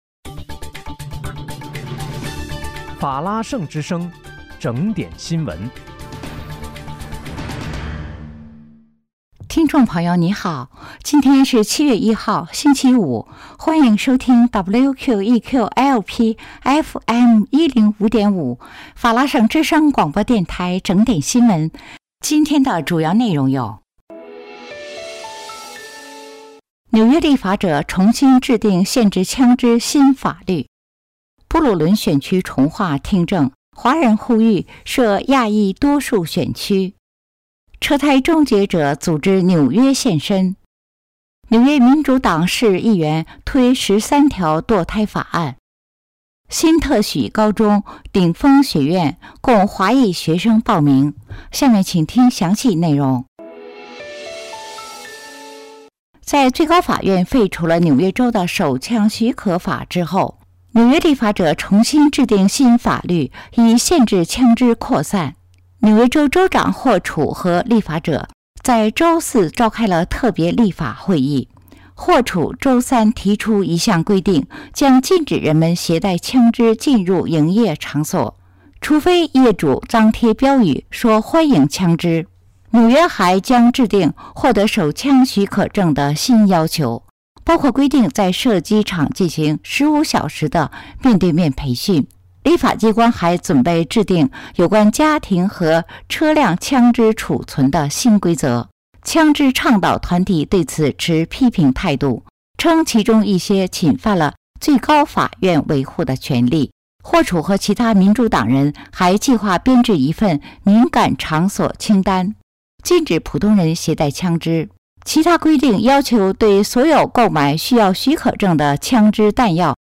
7月1日（星期五）纽约整点新闻
今天是7月1号，星期五，欢迎收听WQEQ-LP FM105.5法拉盛之声广播电台整点新闻。